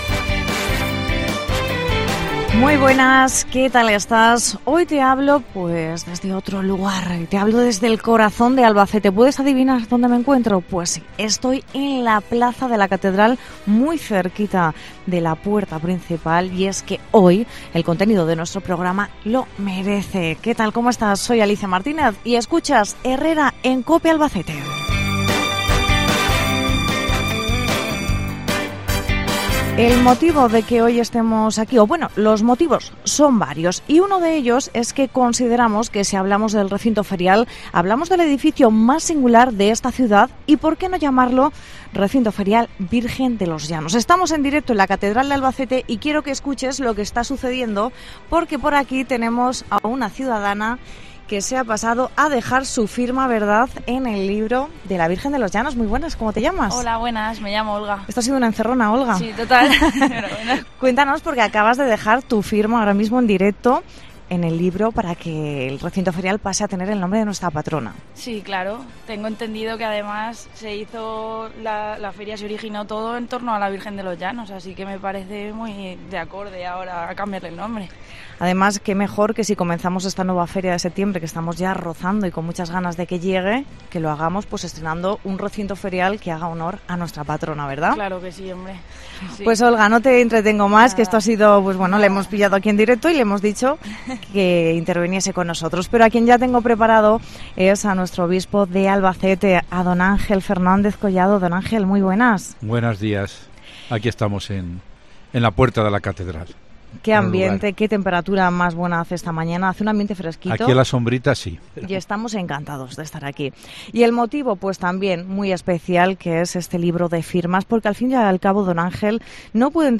Celebramos con un programa especial a las puertas de la Catedral el apoyo de la ciudadanía a la iniciativa de Cope Albacete